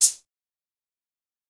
drop_cancel.ogg